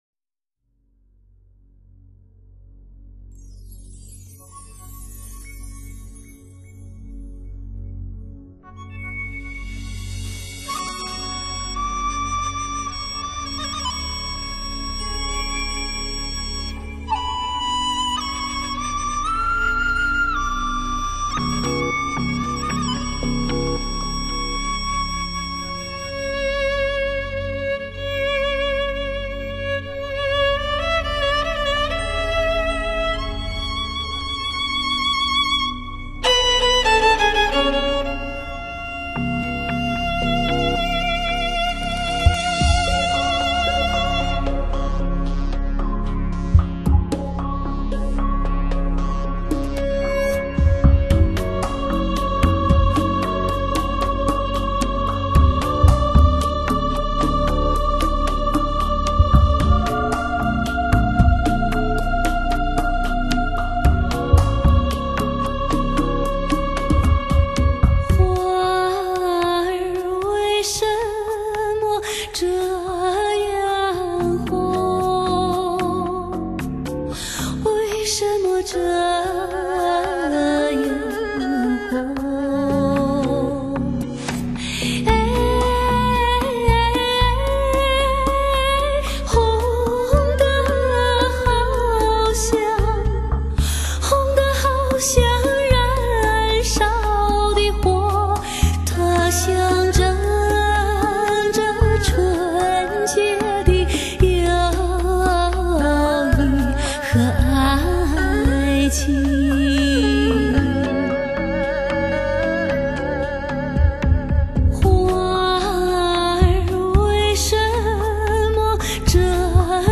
用迷幻、空灵的人声和电子音色营造出柔美、神秘、诡异的天籁之声